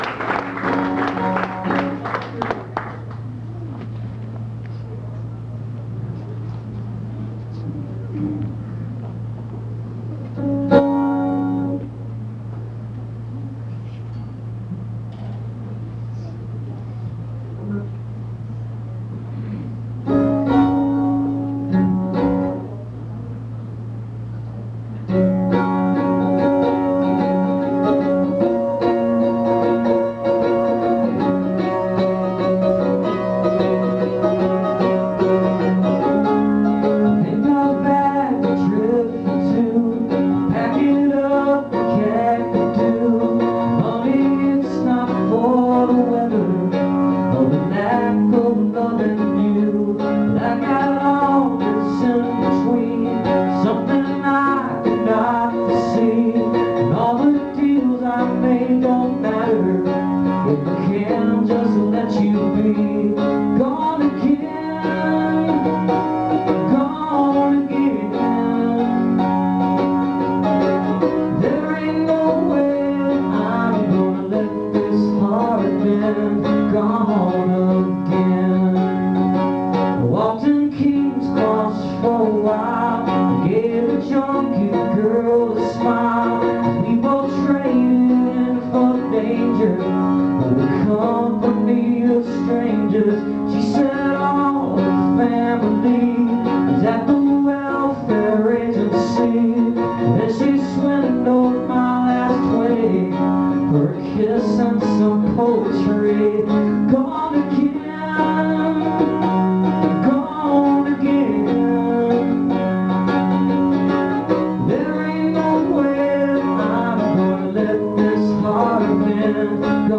(songwriters in the round)